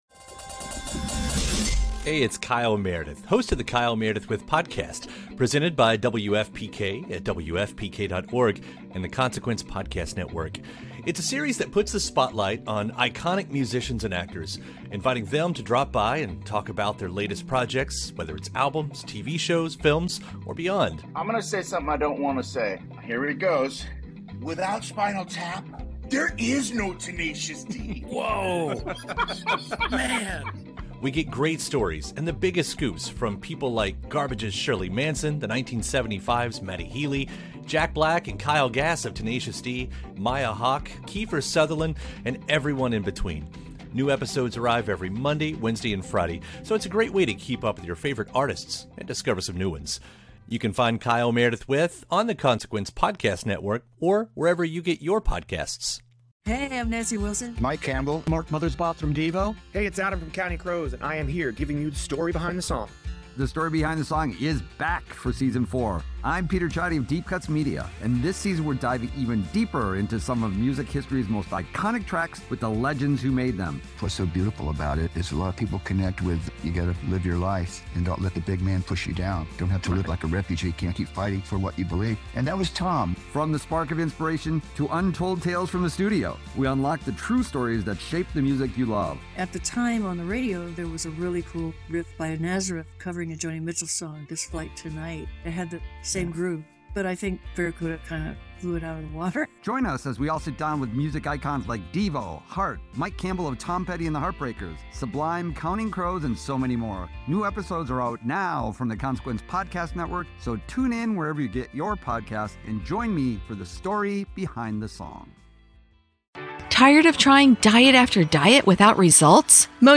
ICYMI Patrick Stump’s First Interview.